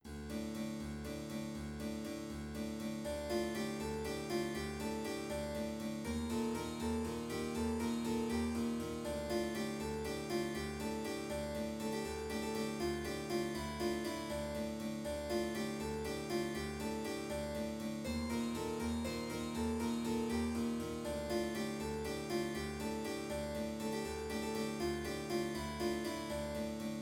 background music added to the game